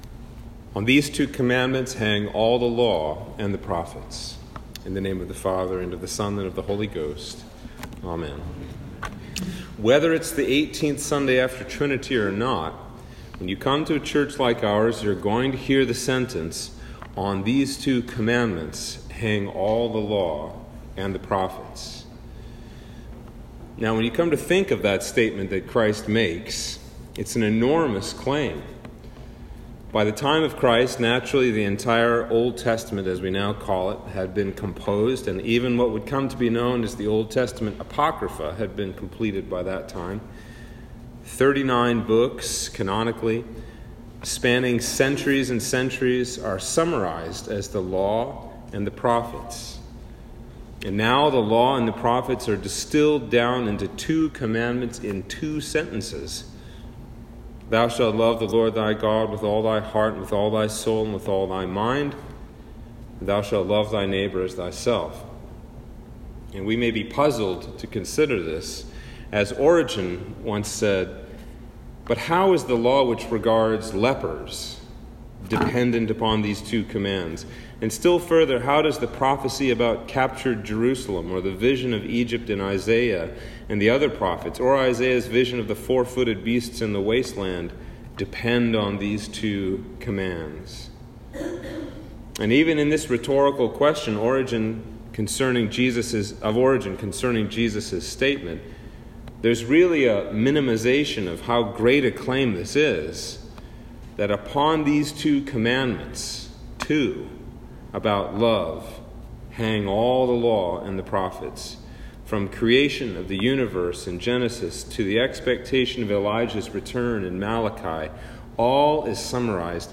Sermon for Trinity 18 - 2021